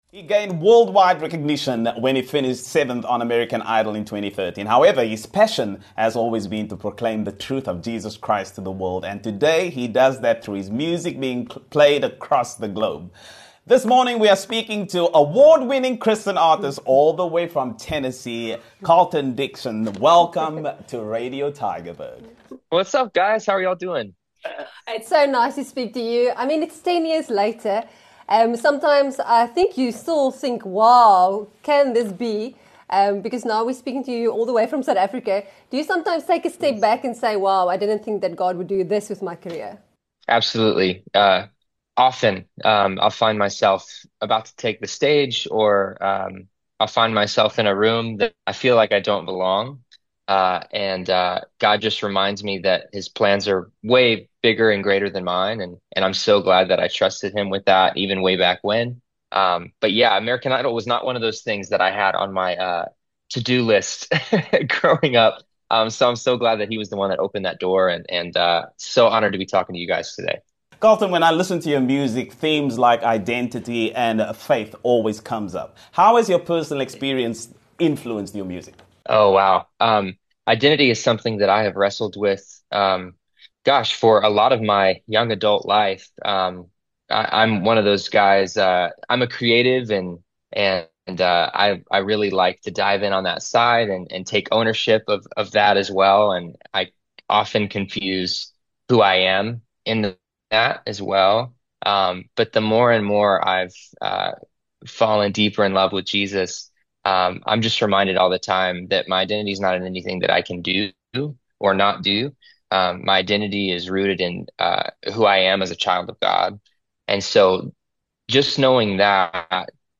Die Real Brekfis (06:00-09:00) gesels met Internationale sanger, Colton Dixon